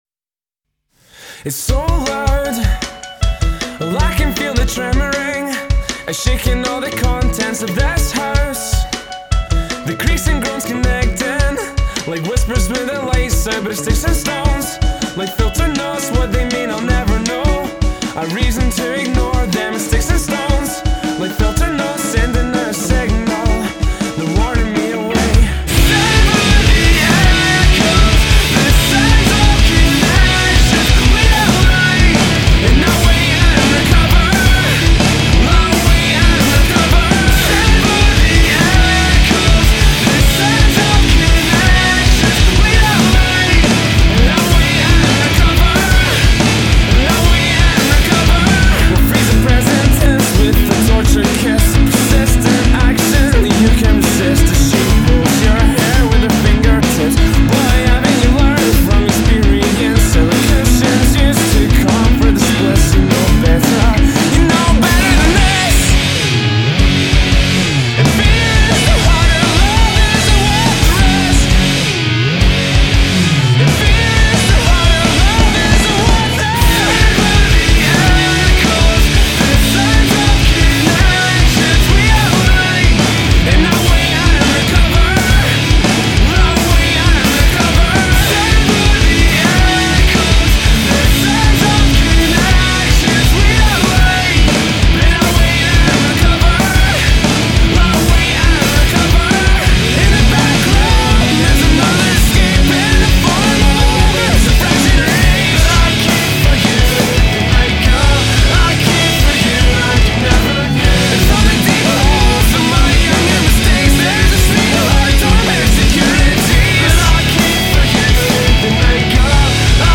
goes into a more sparse sound